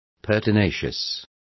Complete with pronunciation of the translation of pertinacious.